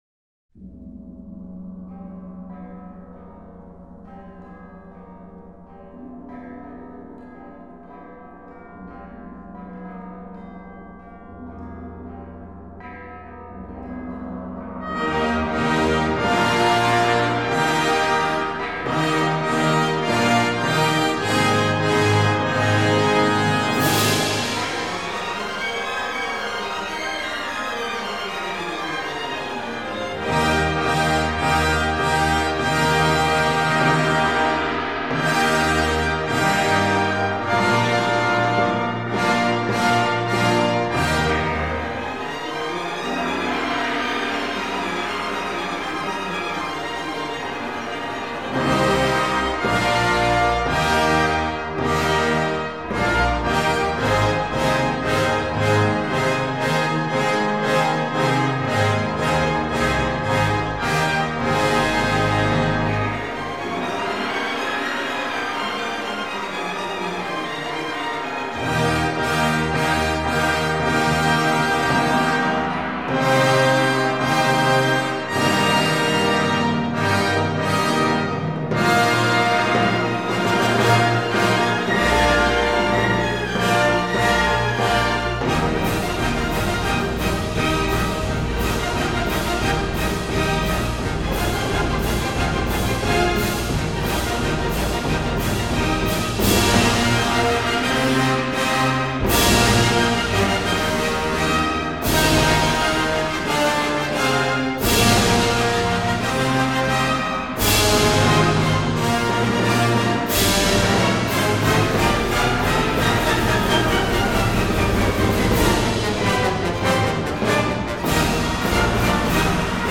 файл) 6,92 Мб Увертюра «1812 год» П.И. Чайковского (финал) 1